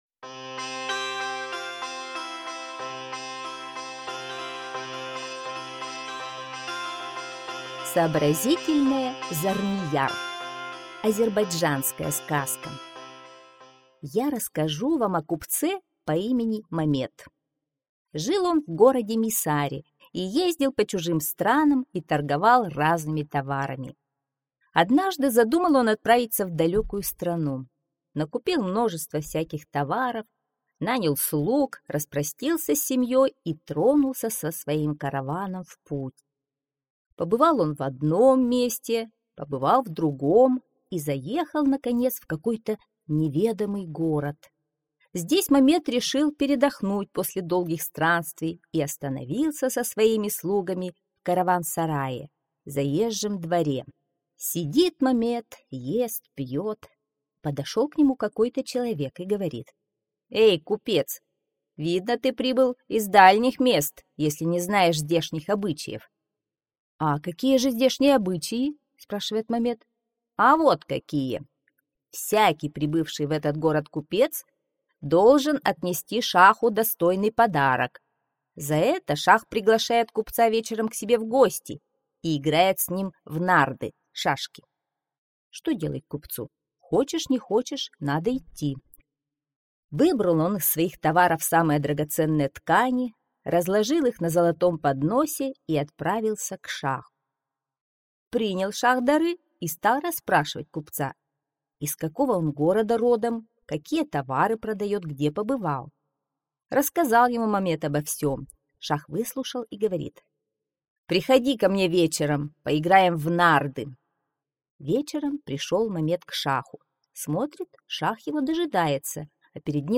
Аудиосказка «Сообразительная Зарнияр»